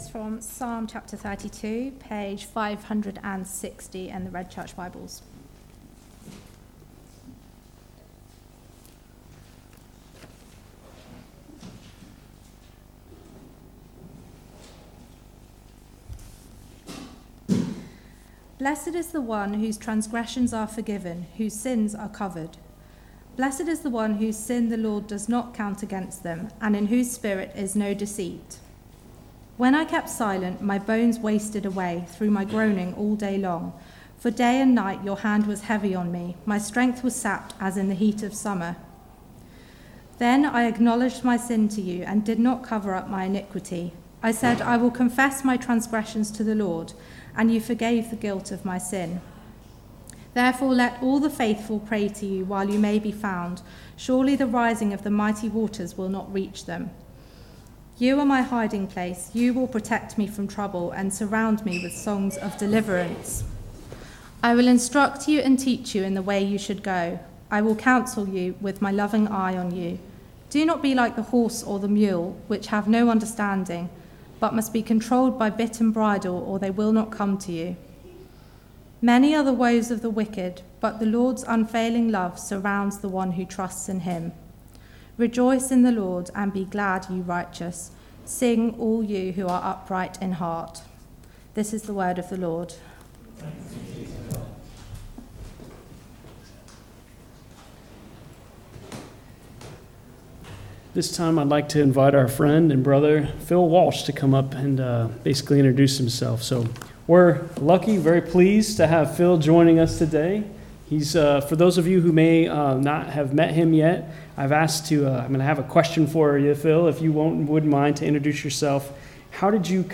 Psalm 32 Service Type: Weekly Service at 4pm « When the foundations are being destroyed what can the righteous do?